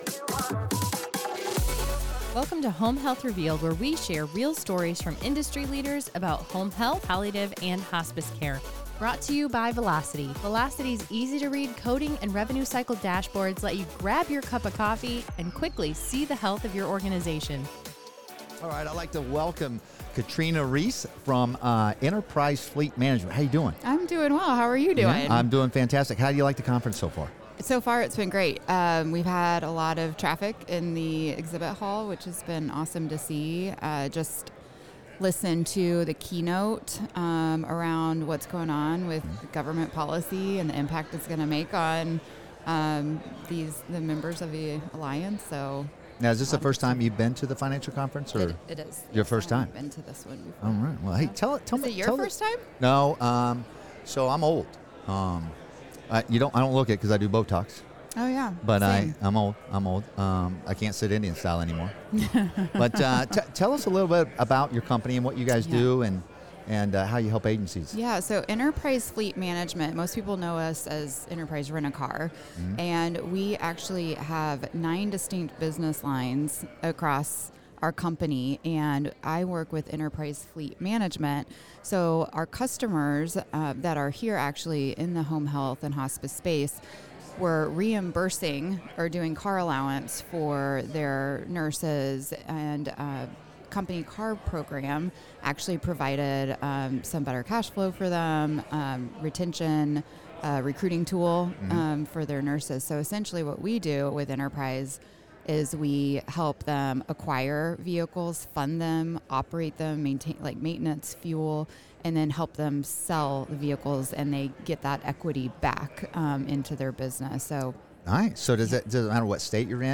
In this episode of Home Health Revealed: Live from the Alliance Conference